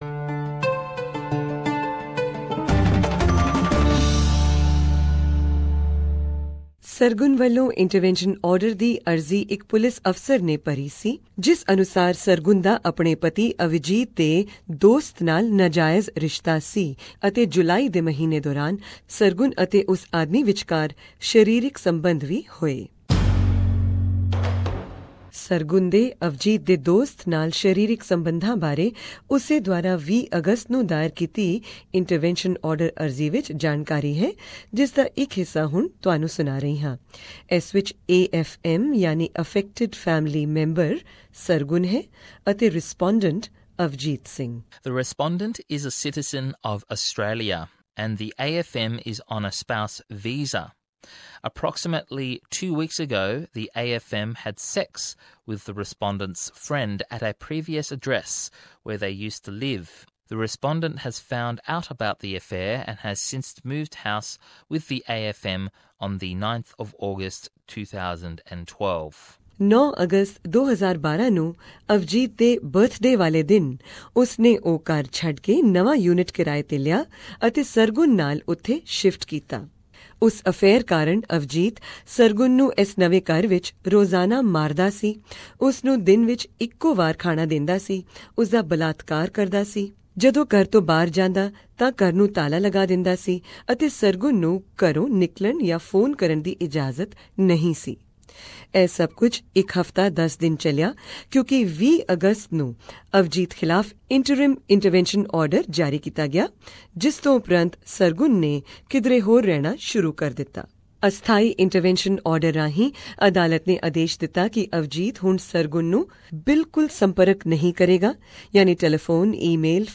This is the second episode of our multi-award winning documentary on family violence in the Indian community of Australia.